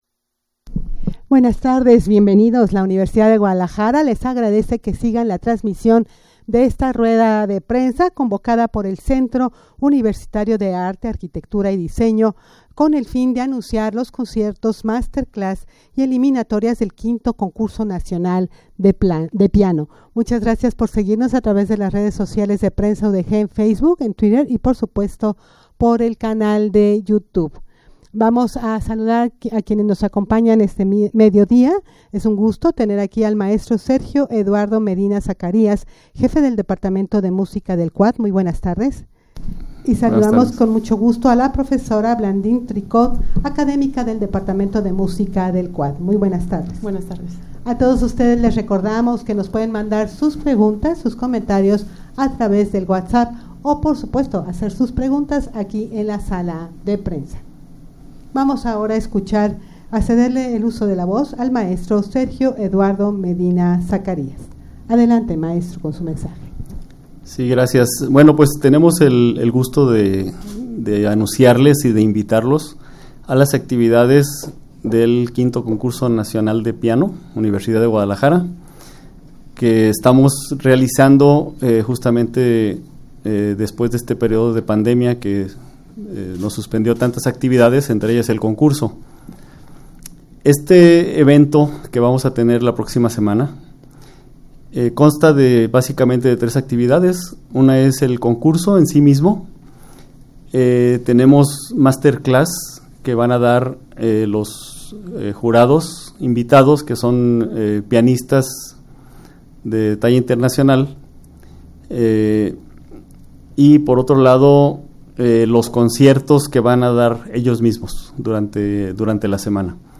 Audio de la Rueda de Prensa
rueda-de-prensa-para-anunciar-los-conciertos-master-class-y-eliminatorias-del-5to-concurso-nacional-de-piano.mp3